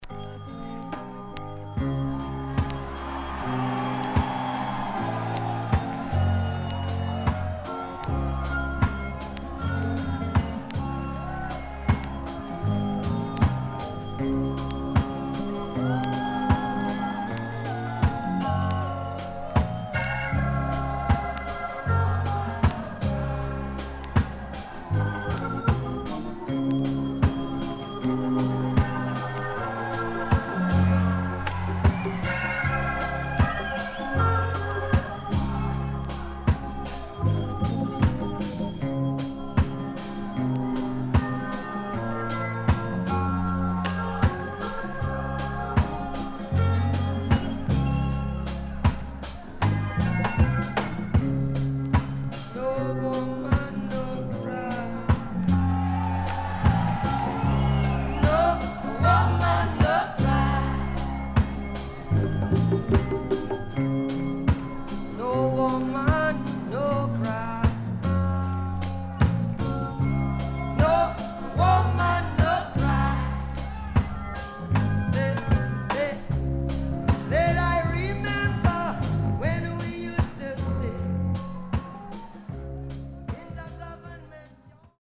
A:75年Live音源.B:73年Live音源.Pressnoise